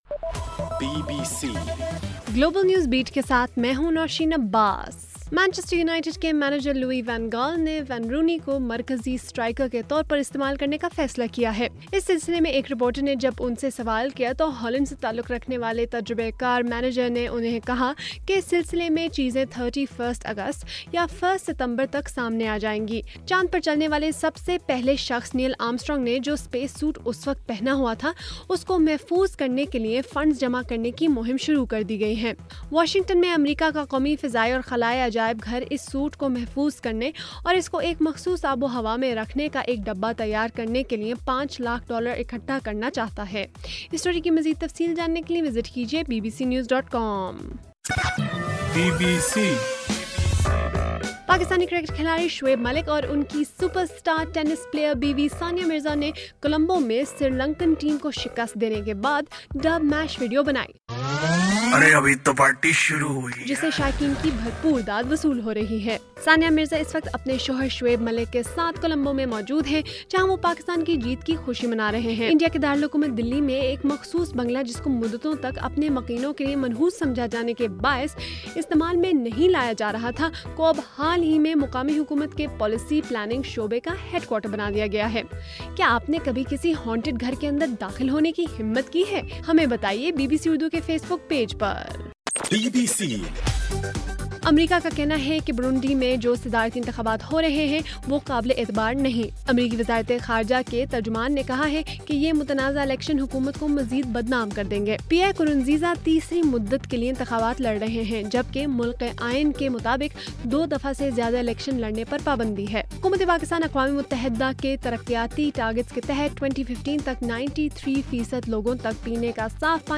جولائی 21: رات 10 بجے کا گلوبل نیوز بیٹ بُلیٹن